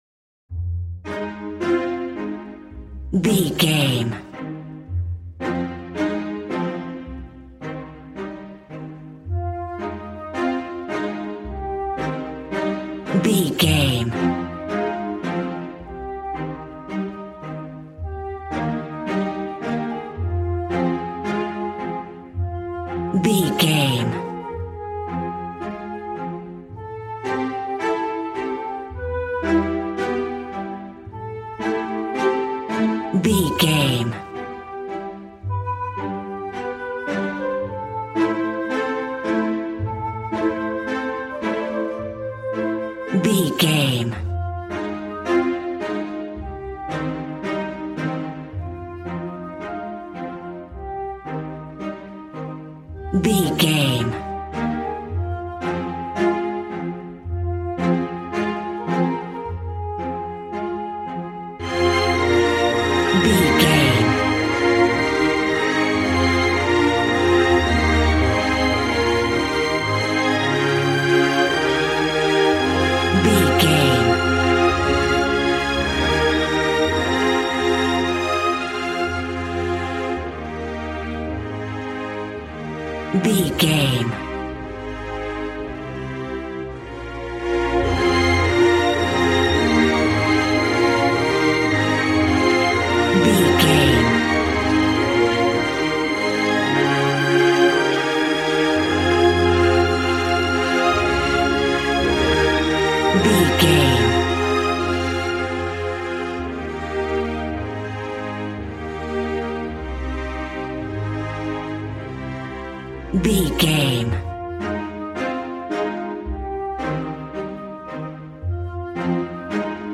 Regal and romantic, a classy piece of classical music.
Aeolian/Minor
regal
cello
double bass